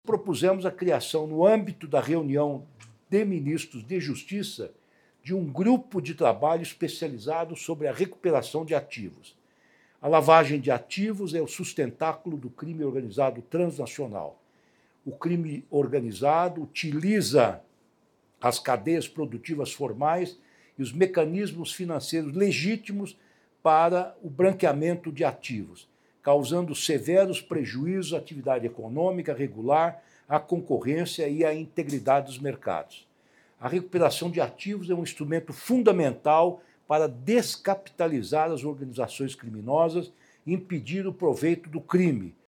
Ministro Ricardo Lewandowski fala sobre o grupo de recuperação de ativos do crime organizado proposto na LXII Reunião de Ministros da Justiça do Mercosul — Ministério da Justiça e Segurança Pública